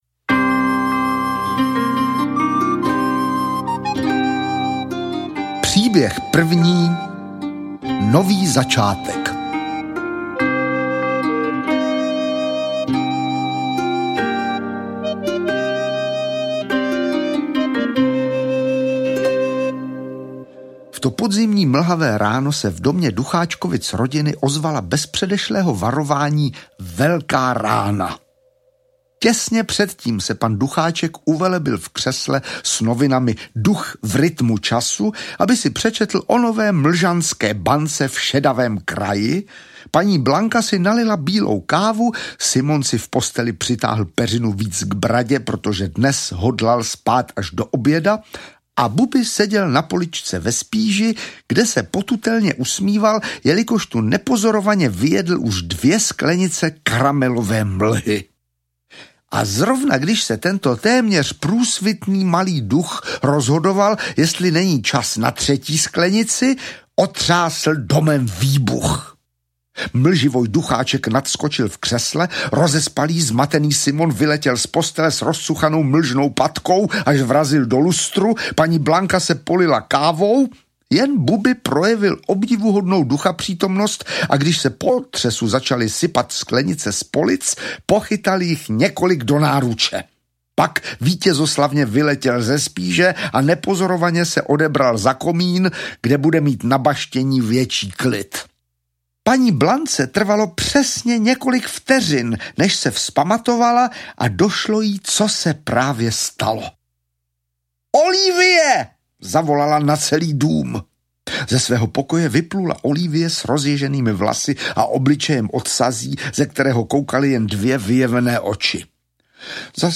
Ukázka z knihy
Oblíbenou knihu Sandry Vebrové čte Václav Vydra.